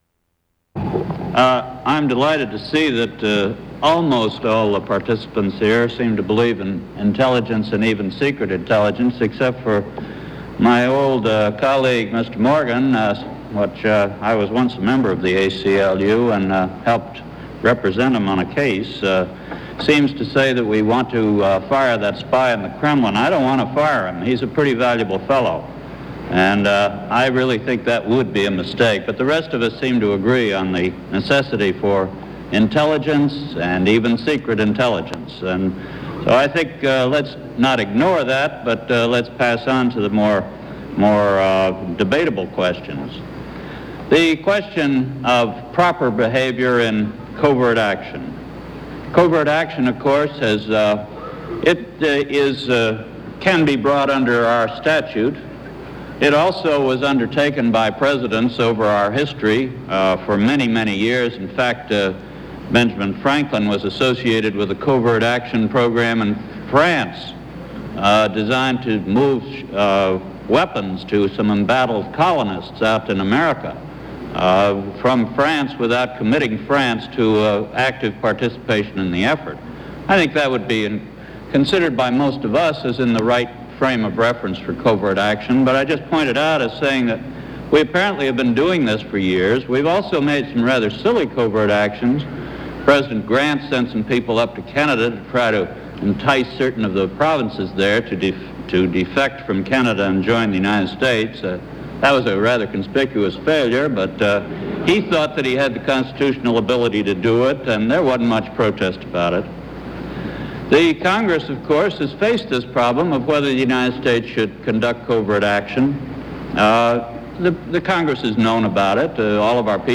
Frank Church addresses a session of the Pacem in Terris Convocation IV on national security and the democratic process